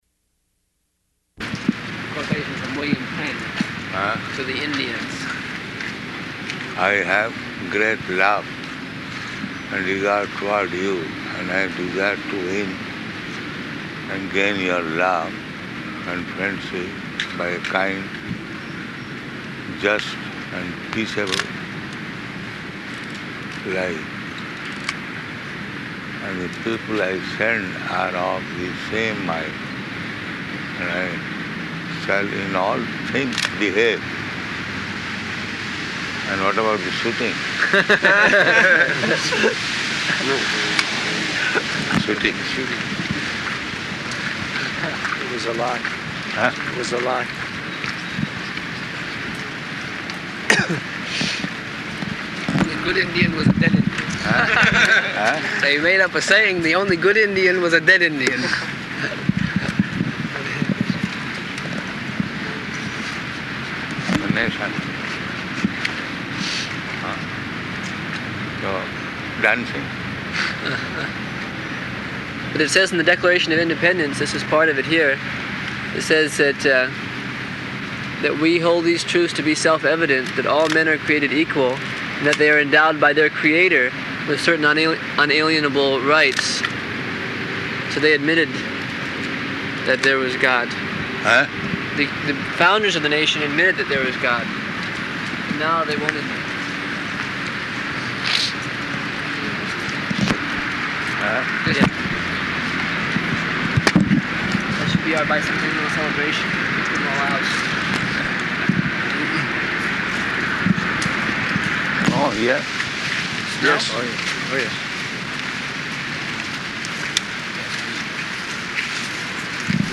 -- Type: Walk Dated: July 12th 1975 Location: Philadelphia Audio file